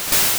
WaterGun_Hit.wav